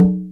TSW CONGA O.wav